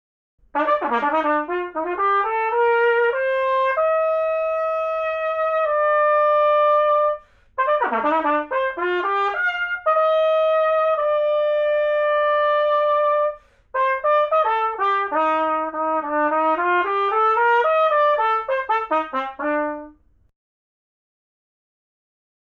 7. Trompet